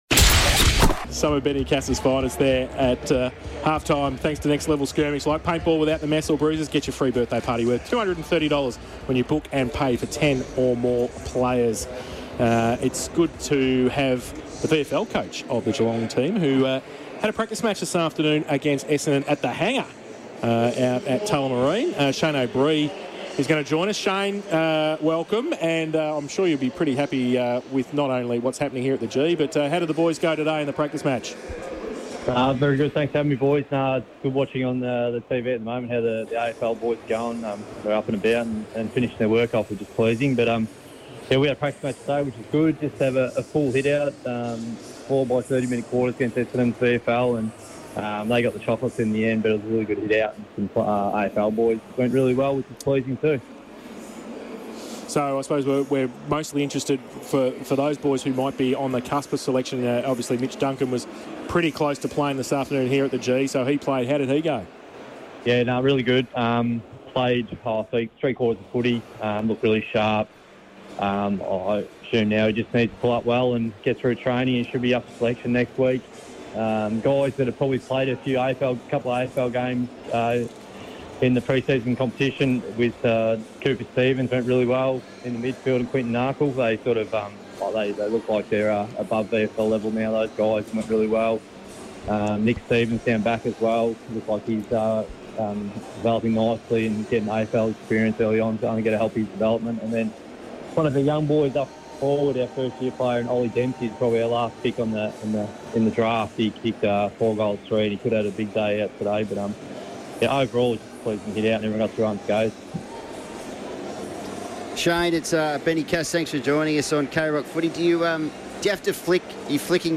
Half time Interview